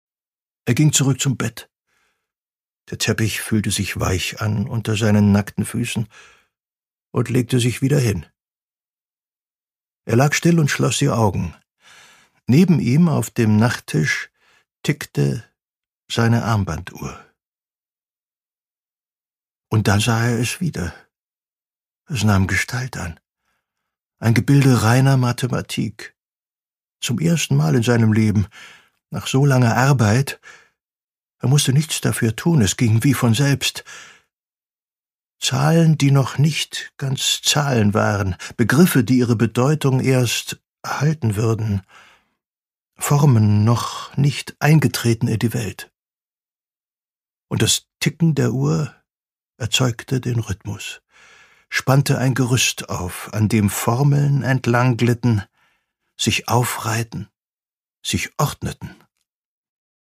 Produkttyp: Hörbuch-Download
Gelesen von: Ulrich Noethen